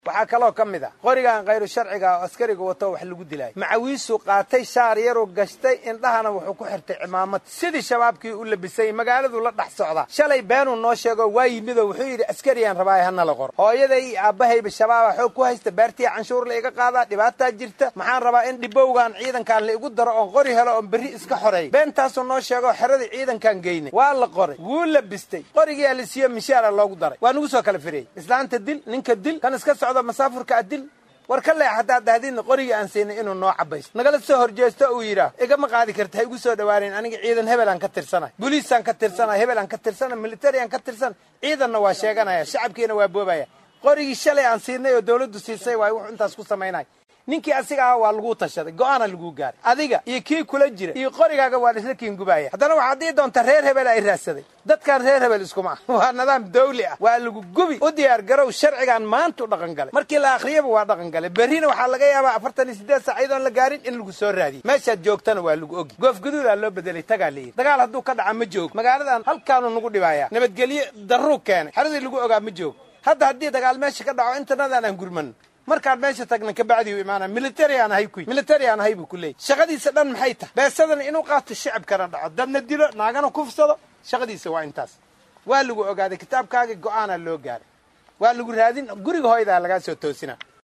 Taliska ciidamada booliska ee gobolka Bay Col. Mahad C/raxmaan   oo saxaafada la hadlay ayaa sheegay ineysan u dulqaadan doonin askarta labista caadiga ah wata ee ku sugan magaalada Baydhabo.